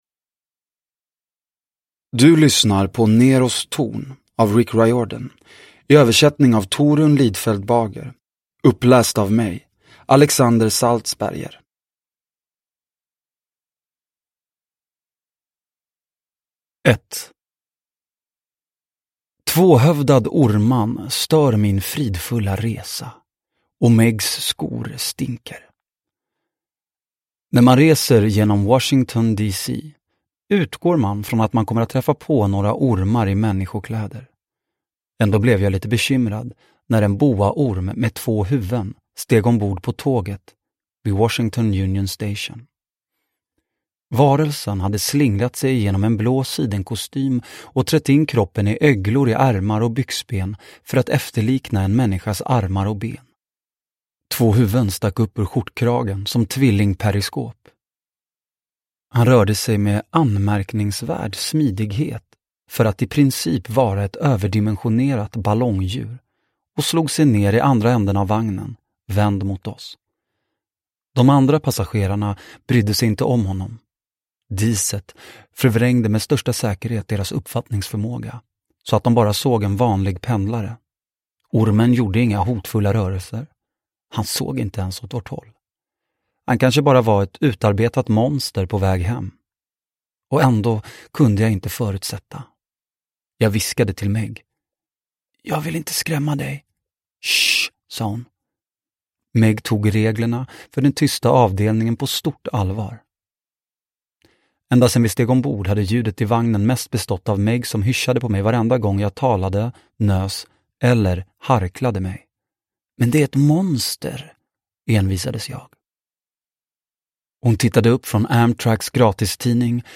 Neros torn – Ljudbok – Laddas ner